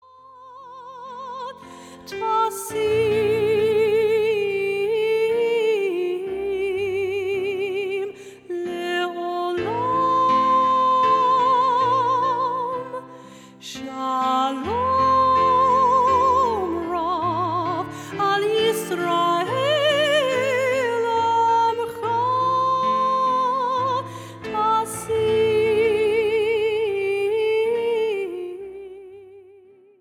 popular adult contemporary worship songs